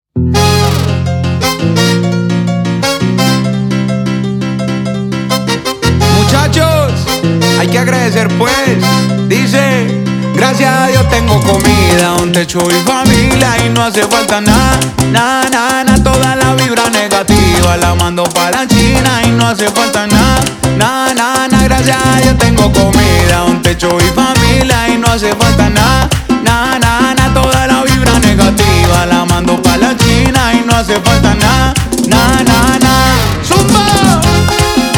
Страстные ритмы латино
Urbano latino Latin
Жанр: Латино